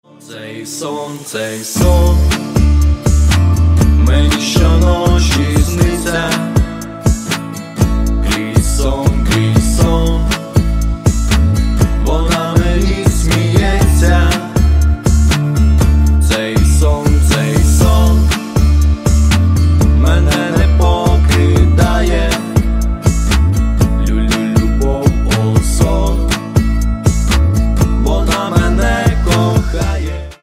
Кавер И Пародийные Рингтоны
Поп Рингтоны